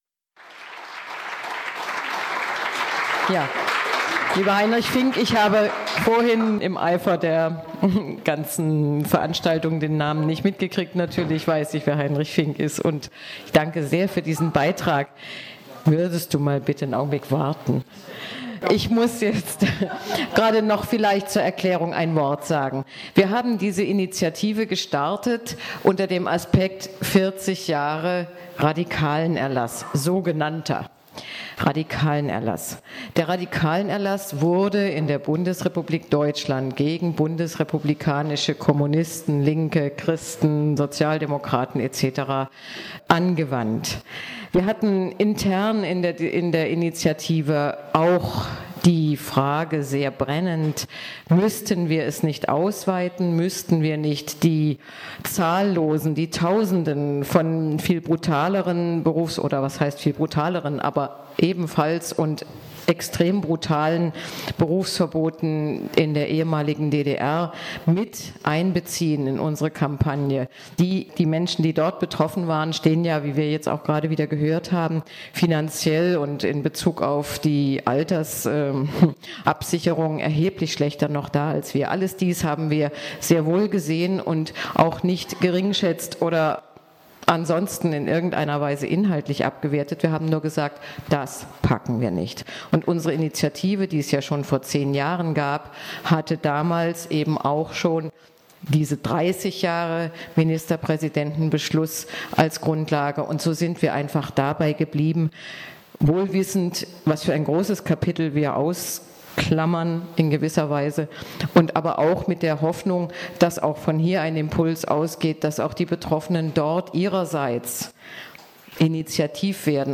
Polithistorische Revue mit 17 Zeitzeuginnen und Zeitzeugen aus 8 Bundesländern,